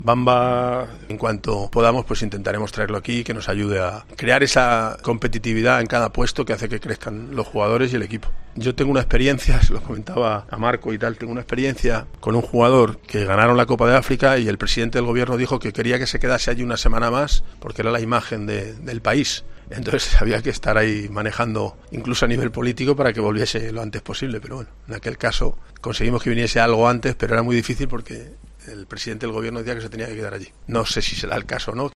Benítez opina sobre el retorno de Bamba